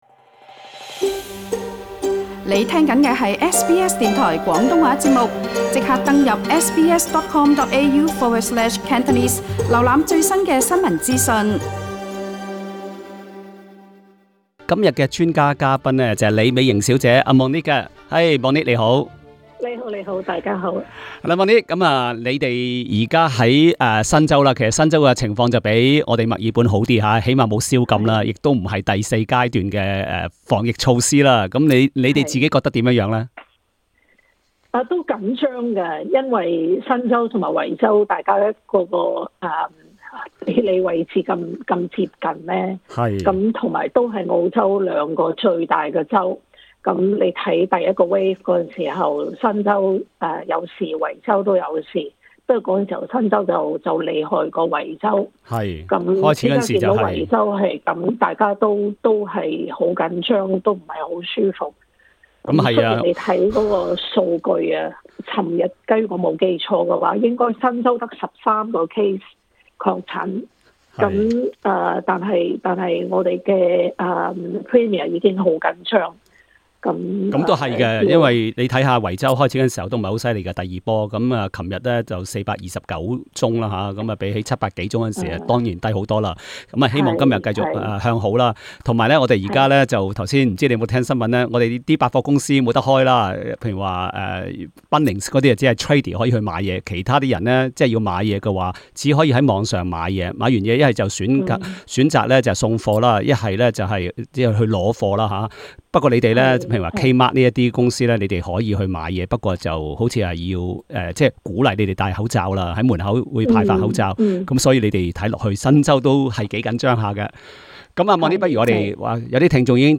Talkback